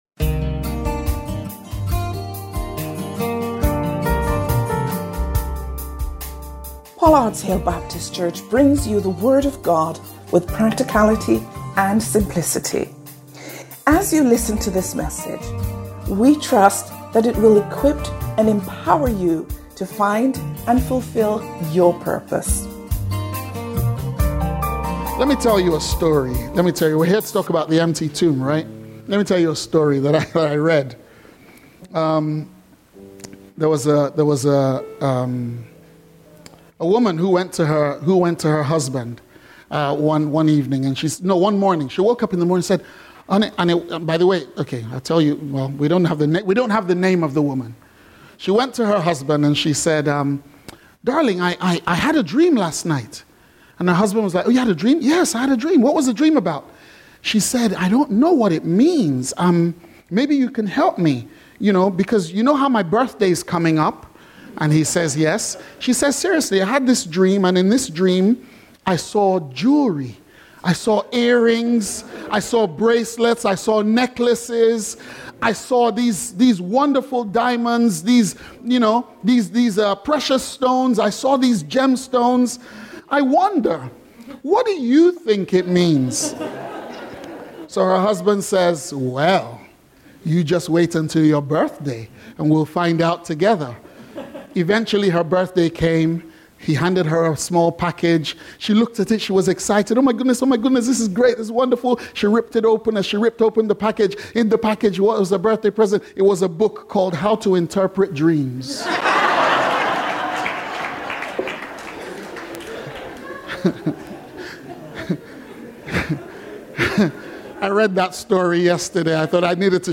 Life would be very different if all of our bills were marked as fully paid and we owed nothing to anyone. In this sermon series, we recognise that as Christians, our account with God does look like that, and we can see the empty tomb because Jesus ensured that everything has been fully paid.